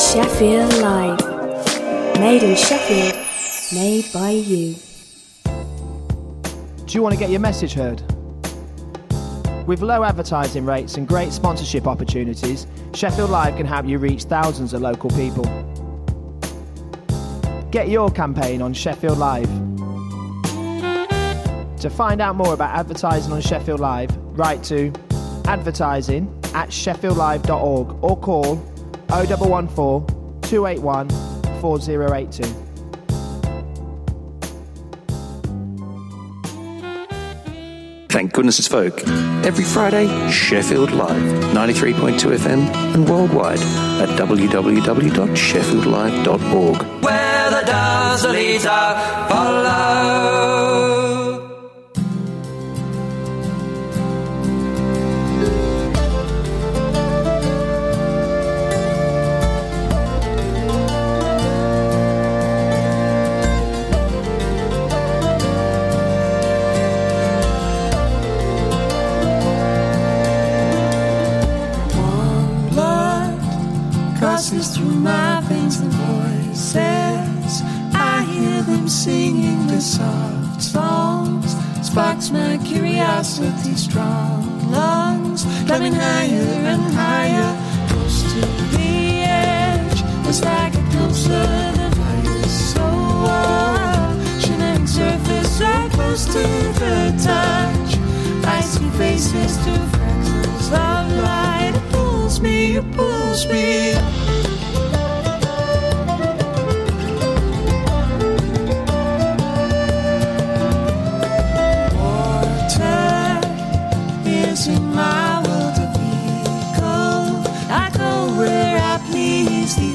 Traditional folk music from the British Isles